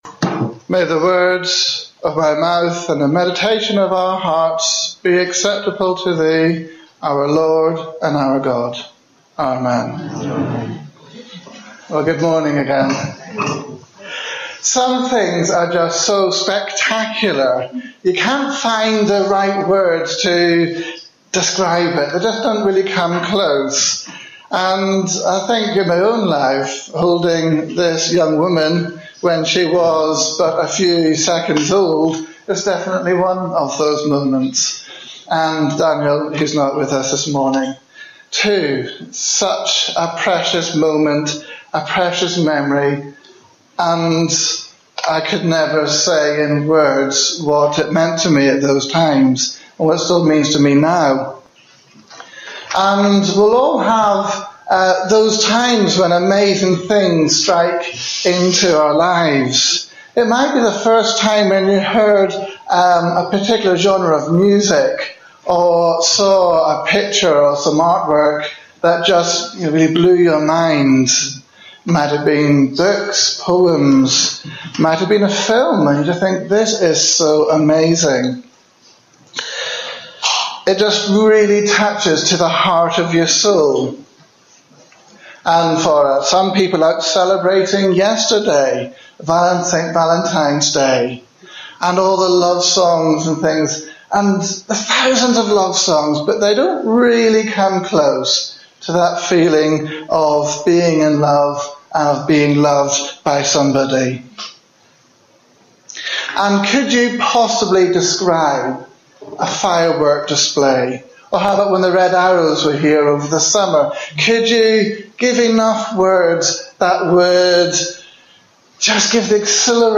Sermon-15-Feb-15.mp3